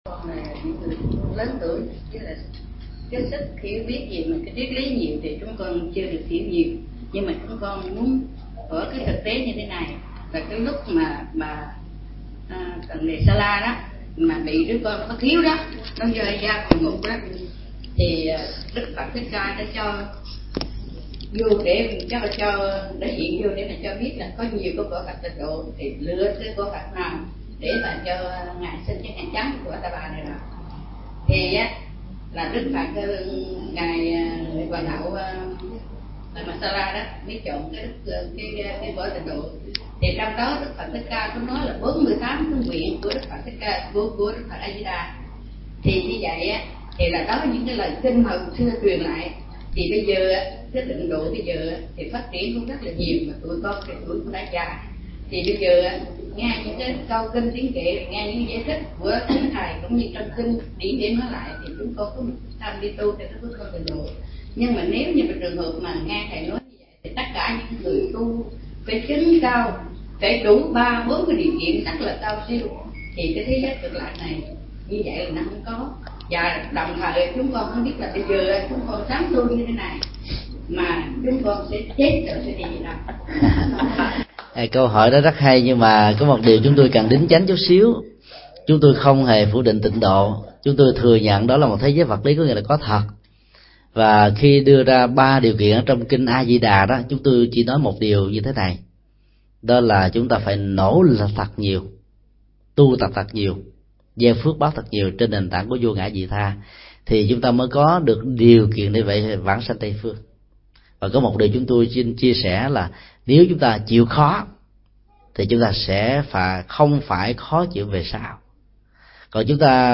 Vấn đáp: Tịnh độ trong Kinh A Di Đà